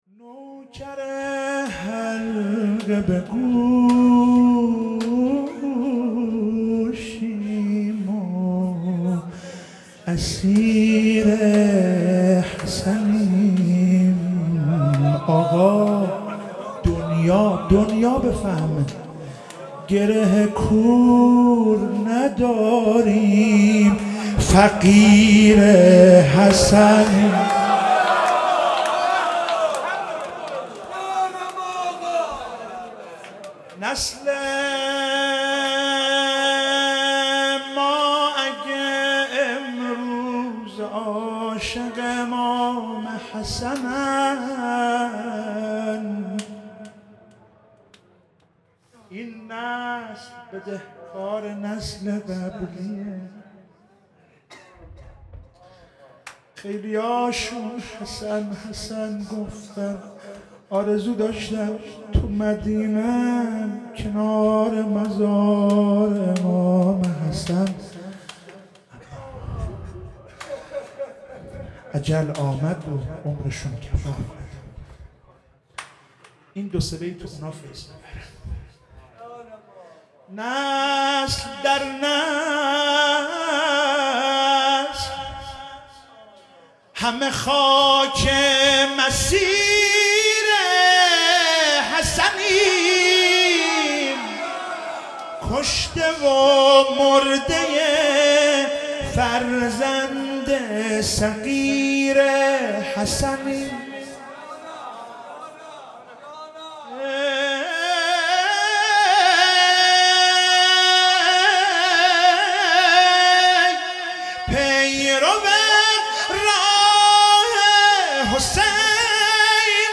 شب ششم محرم96 - شعرخوانی - نوکر حلقه به گوشیم